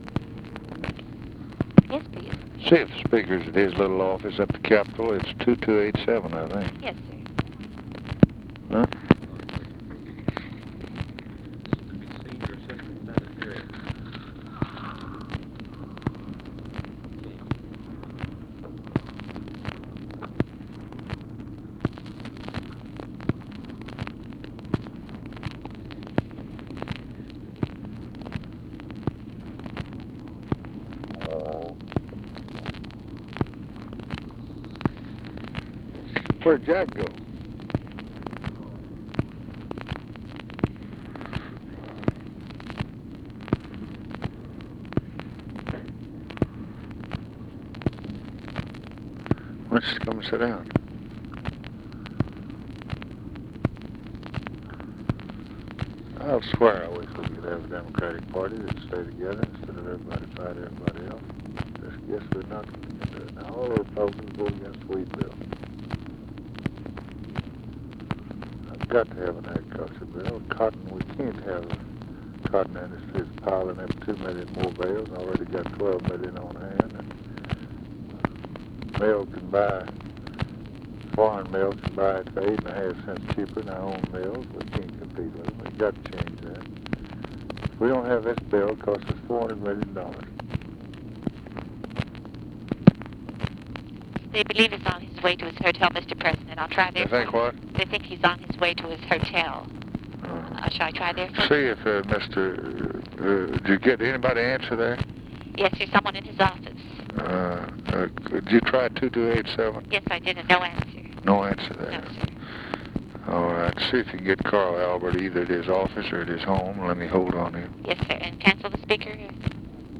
LBJ ASKS OPERATOR TO CALL JOHN MCCORMACK, THEN CARL ALBERT, SINCE MCCORMACK UNAVAILABLE; LBJ DISCUSSES LEGISLATIVE STRATEGY ON WHEAT-COTTON FARM BILL, FOOD STAMP BILL WITH UNIDENTIFIED MALE IN OFFICE WHILE WAITING FOR CALL TO ALBERT; TEXTILE MILLS
Conversation with CARL ALBERT and OFFICE CONVERSATION, March 10, 1964